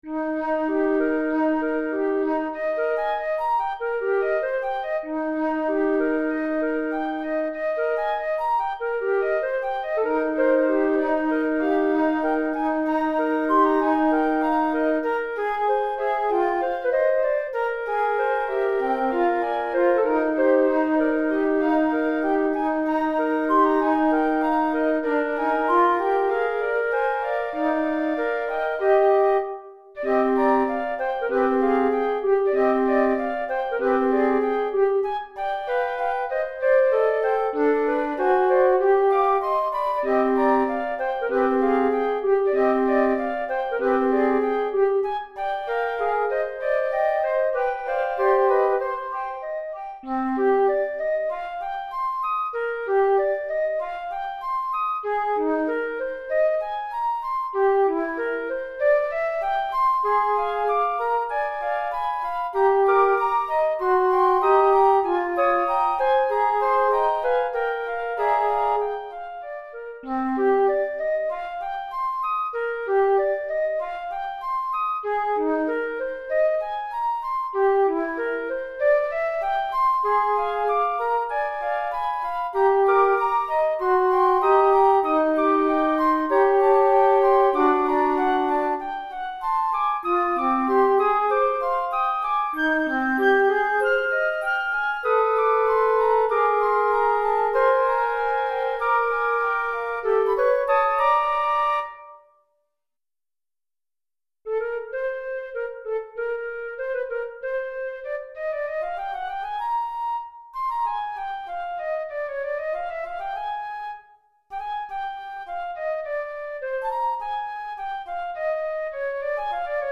4 Flûtes Traversières